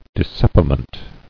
[dis·sep·i·ment]